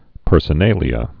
(pûrsə-nālē-ə, -nālyə)